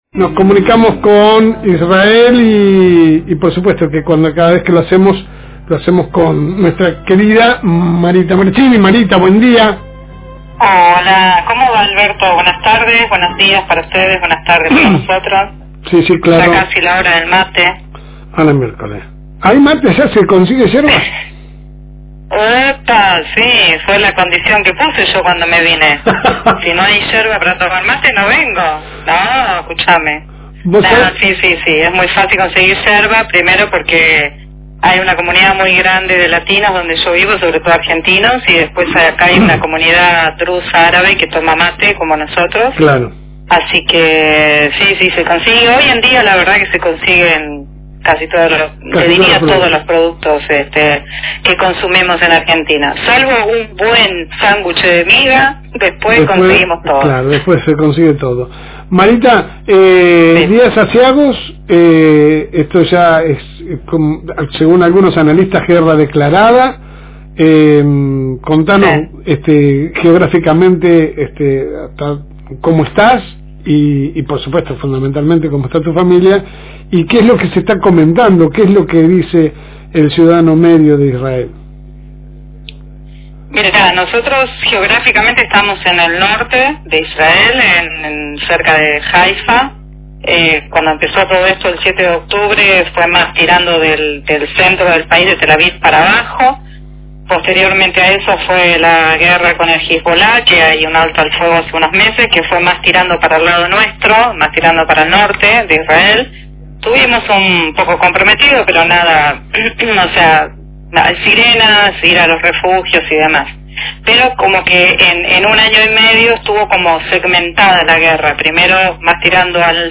En comunicación telefónica pudimos charlar con la lobense para que nos contara, primero como están de salud ella y su familia para después pasar a consultar como viven el conflicto entre los dos países (Israel e Irán), que en los últimos días subió la intensidad de los ataques.